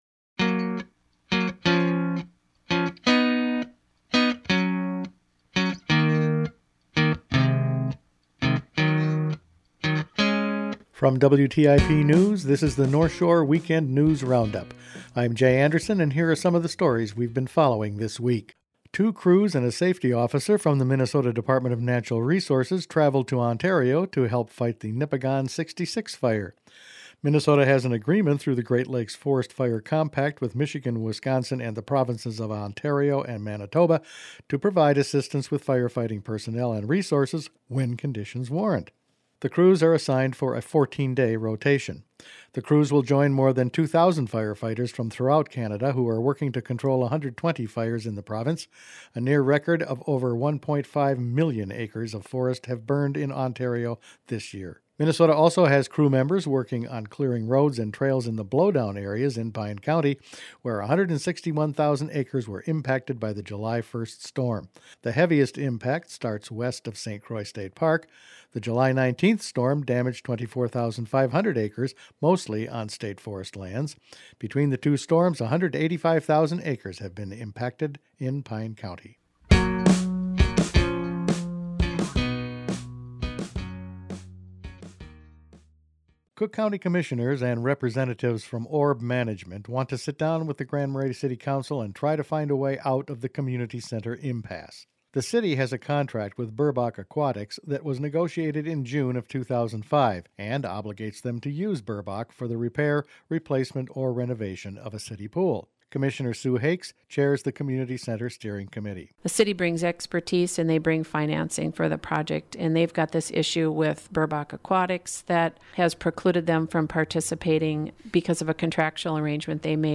Each weekend WTIP news produces a round up of the news stories they’ve been following this week. Minnesota firefighters went to Ontario, a Lake Superior water quality meeting is scheduled here next month, the county and city will sit down and talk about ways to stop treading water over the community center pool and an assisted living proposal is floated for Grand Marais…all in this week’s news.